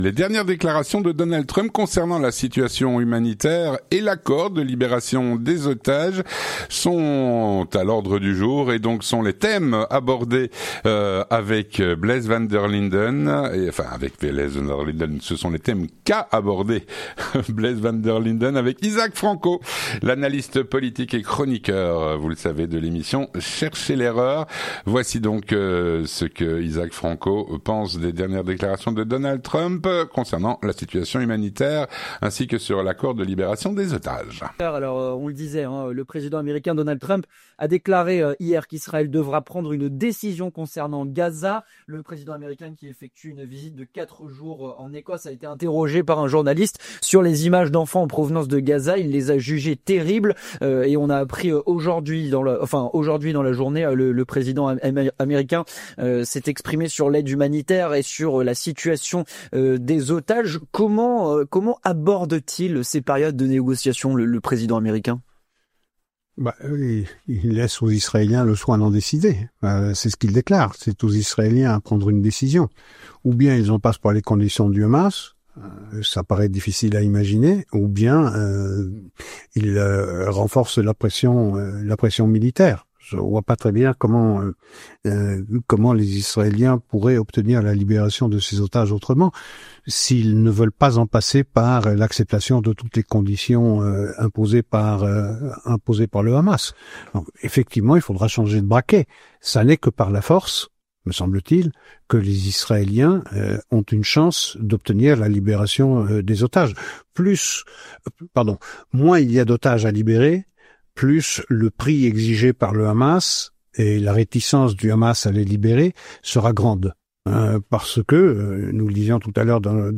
L'entretien du 18H - Les dernières déclarations de Donald Trump concernant la situation humanitaire et l’accord de libération des otages.